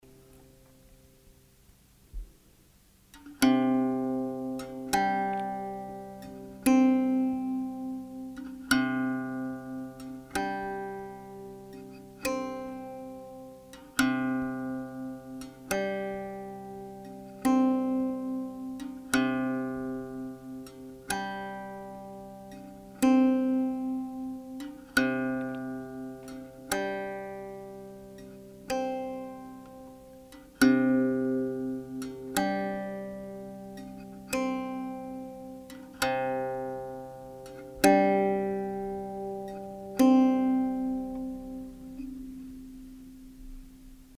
三味線・音源
音：開放弦の音
駒：象牙（オリジナル）